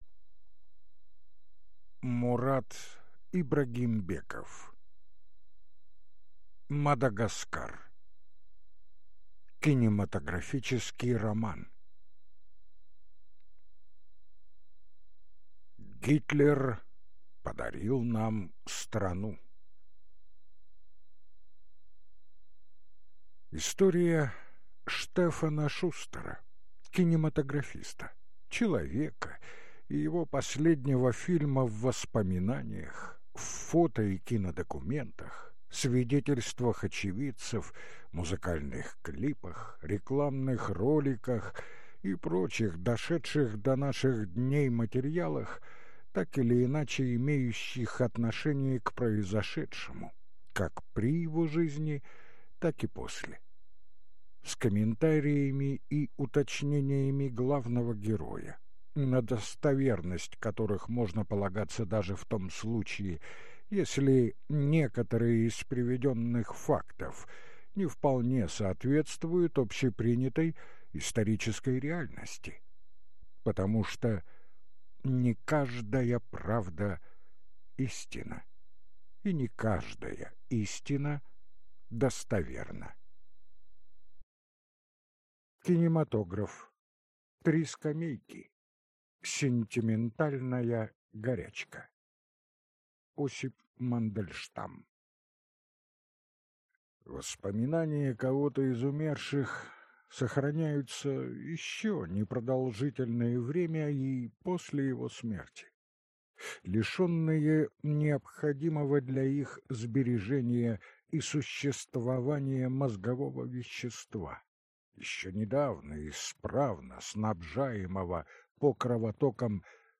Аудиокнига Мадагаскар. Кинематографический роман | Библиотека аудиокниг